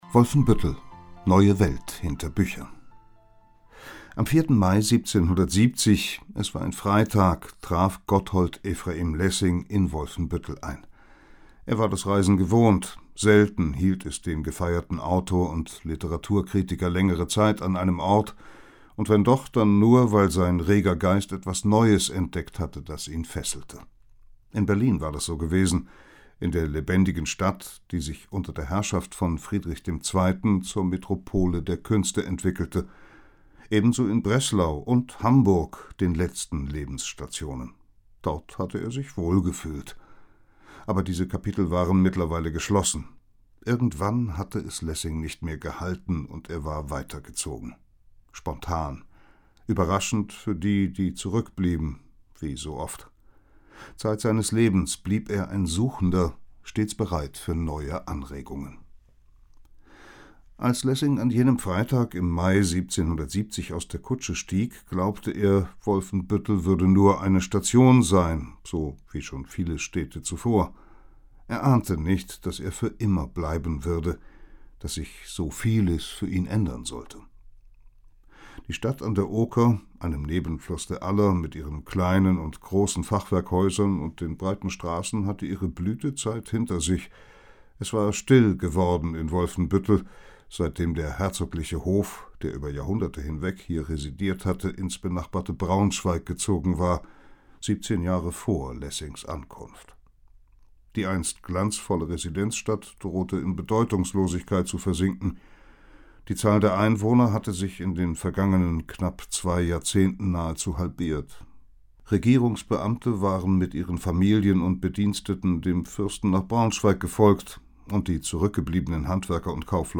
Hörbuch mit 3 CDs.